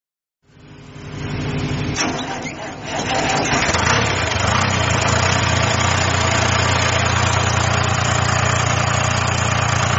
TBAM26-eg-diesel_eng_sound_48.mp3